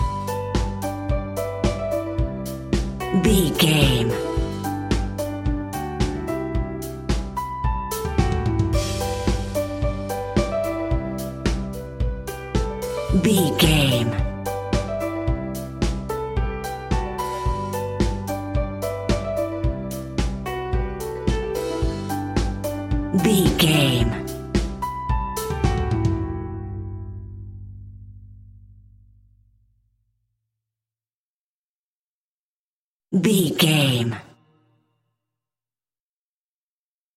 Motivation Business Pop Rock Music 30 Sec.
Ionian/Major
pop rock
indie pop
energetic
uplifting
upbeat
groovy
guitars
bass
drums
piano
organ